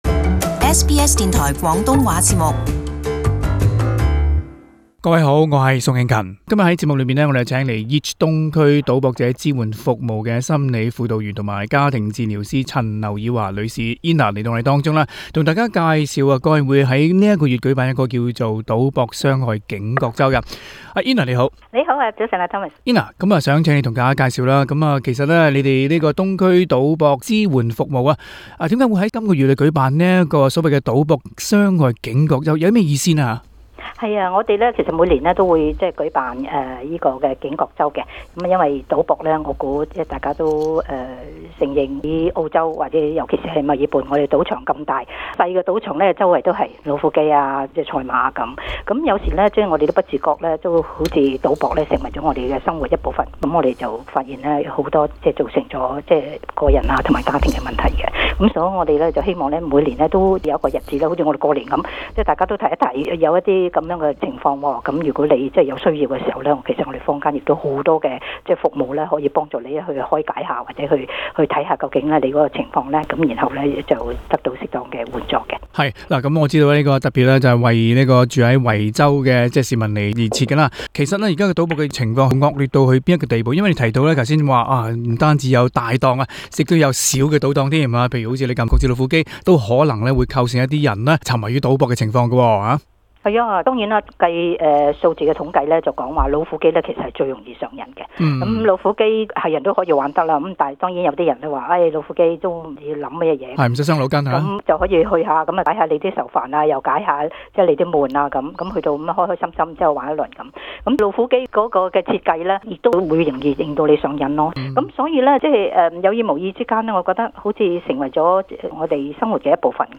【社區專訪】賭癮戒得甩嗎？